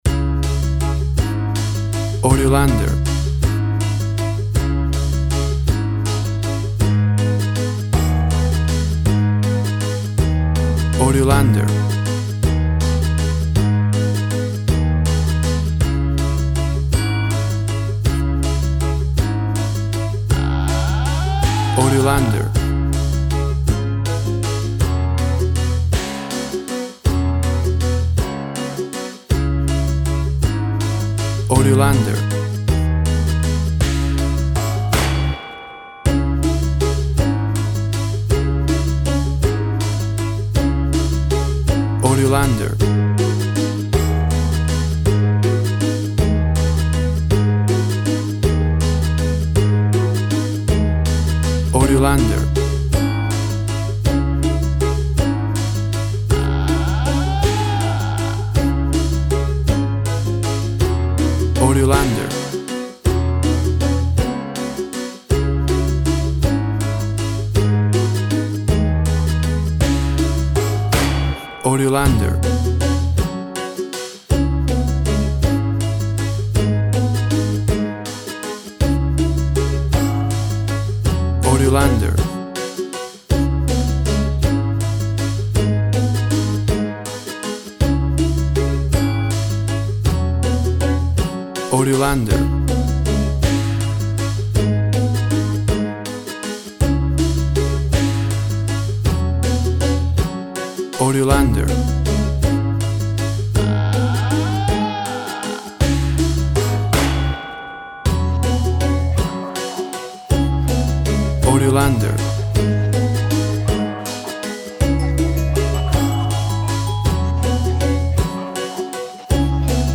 WAV Sample Rate 16-Bit Stereo, 44.1 kHz
Tempo (BPM) 76